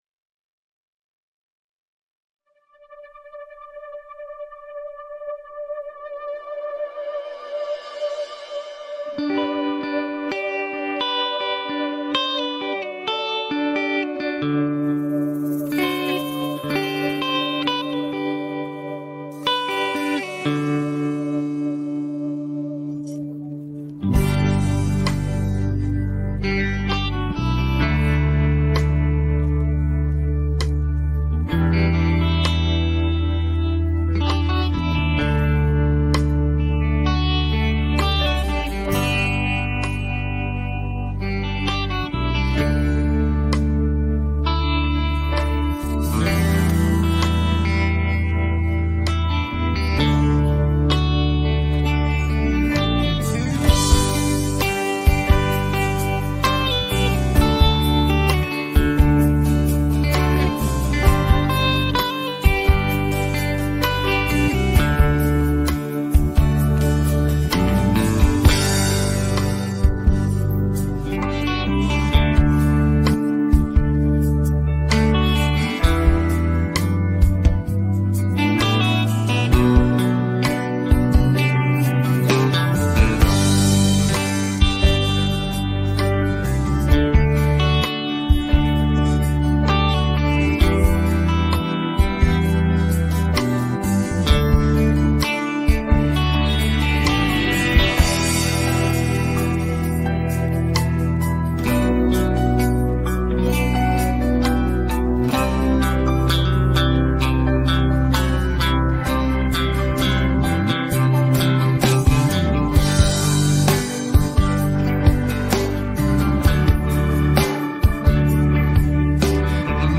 rock караоке 39
Українські хіти караоке